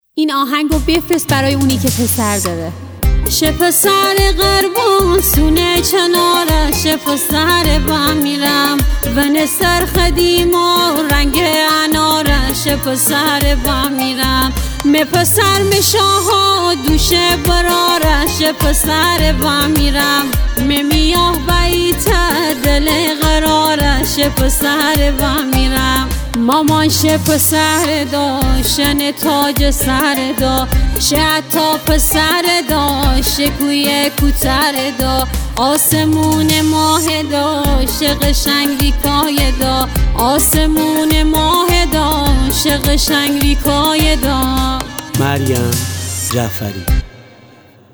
ریتمیک ( تکدست )
آهنگی در سبک آهنگ های شاد مازندرانی